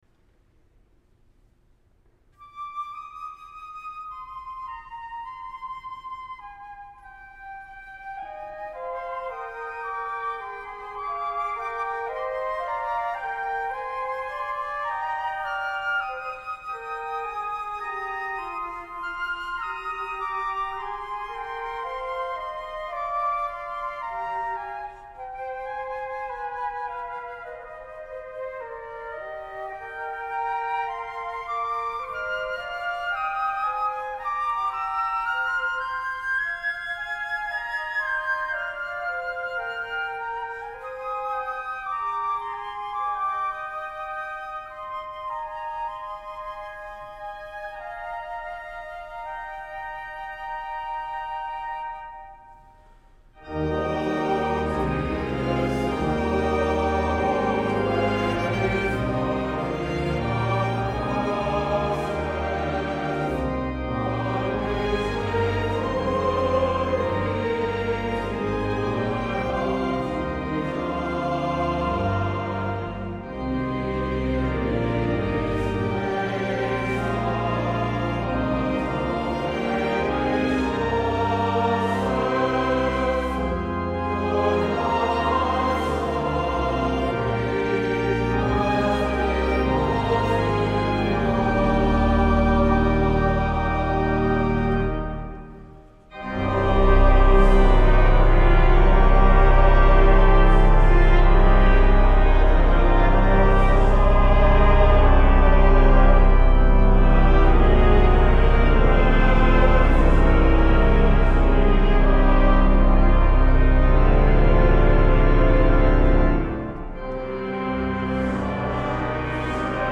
hill-of-crosses-2008-st.-olaf-chapel-service.mp3